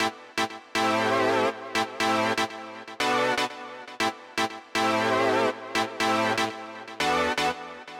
23 ChordSynth PT4.wav